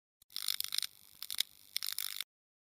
Звуки мерцания
Погрузитесь в мир загадочных аудиоэффектов.